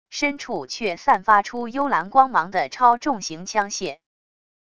深处却散发出幽蓝光芒的超重型枪械wav音频